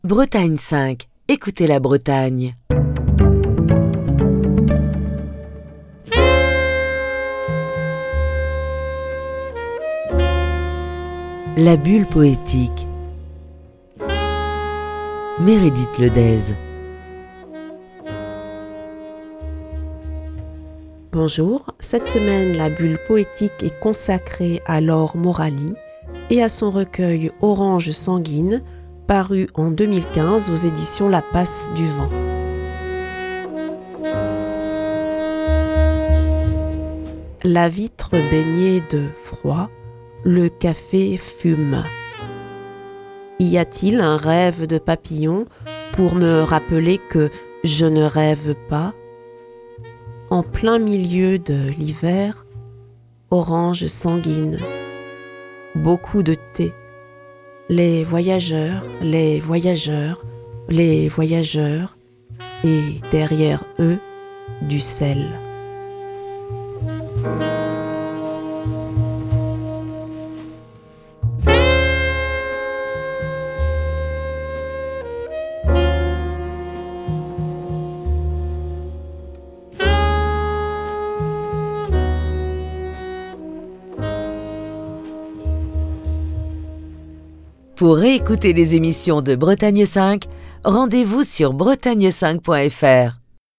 lit quelques poèmes extraits de Orange sanguine, un recueil de Laure Morali, publié en février 2015 aux éditions La Passe du Vent.